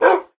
wolf_bark3.ogg